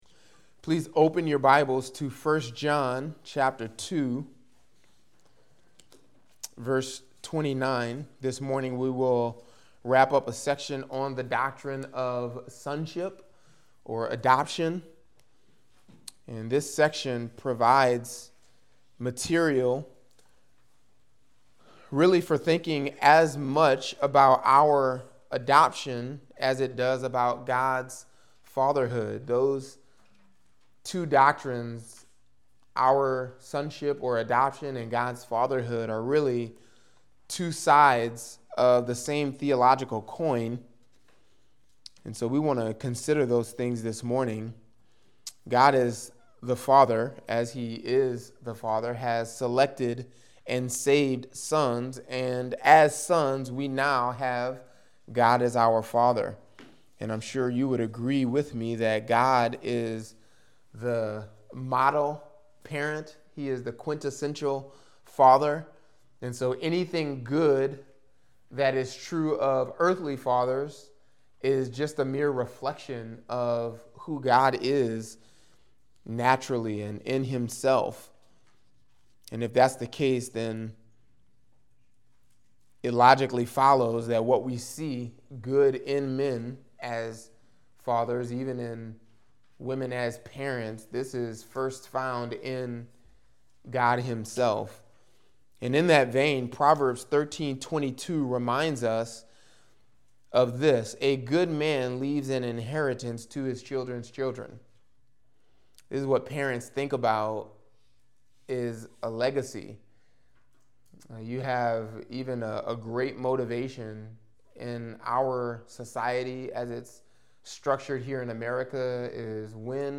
Podcast (gbc-nola-sermons): Play in new window | Download